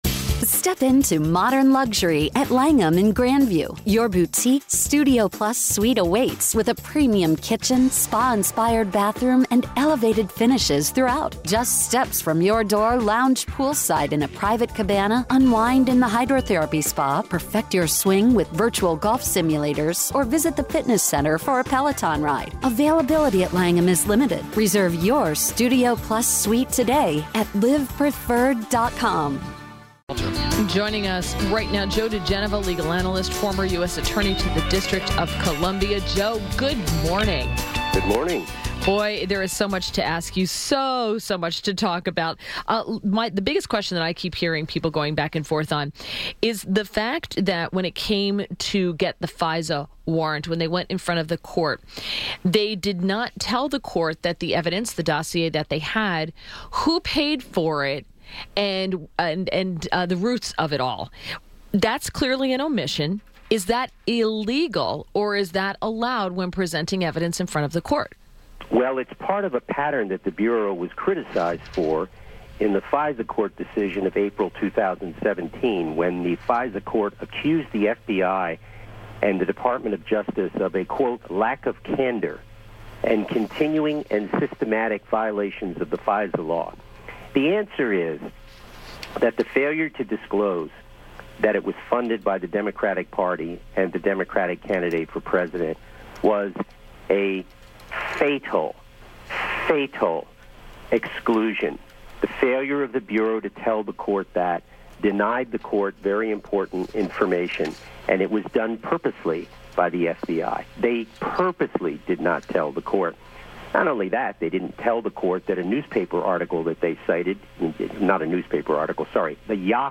WMAL Interview - JOE DIEGENOVA - 02.05.18
INTERVIEW -- JOE DIGENOVA - legal analyst and former U.S. Attorney to the District of Columbia – analyzed the Nunes blockbuster memo.